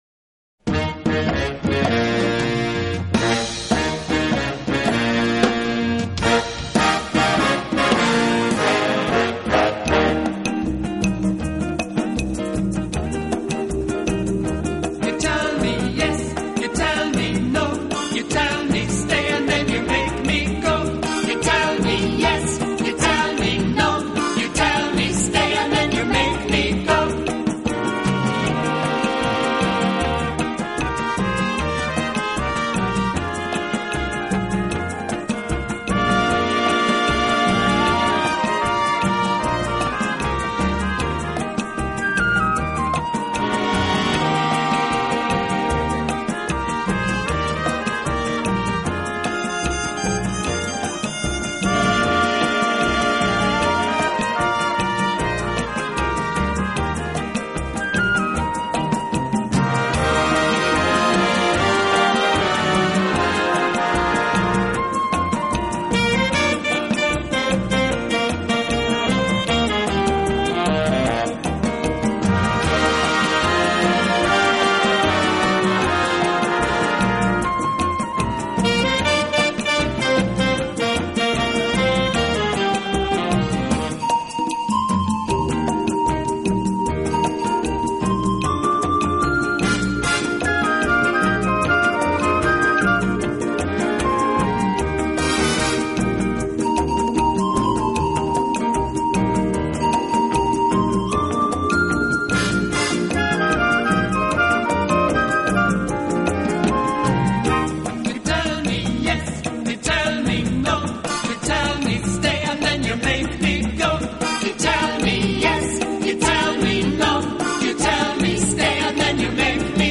【拉丁轻音乐】
無比通透的木管和潤澤透亮的 銅管音色雙互輝映，展現出驚人的定位和音場